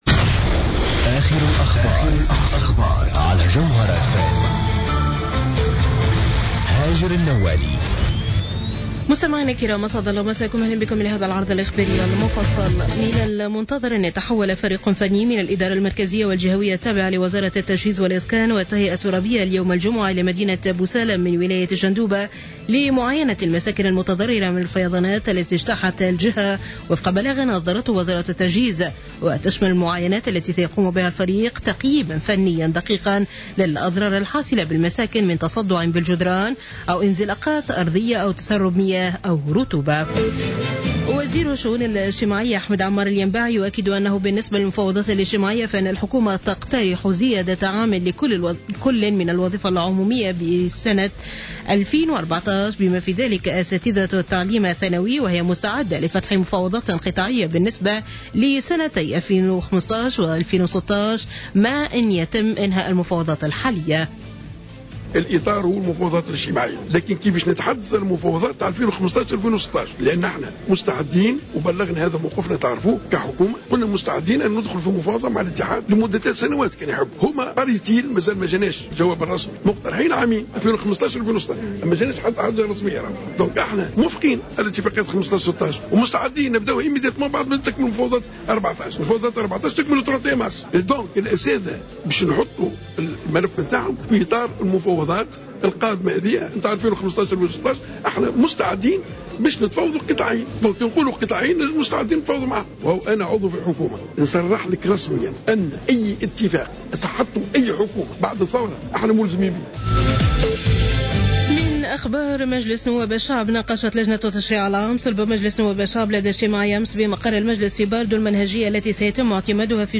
نشرة أخبار منتصف الليل ليوم الجمعة 13 مارس 2015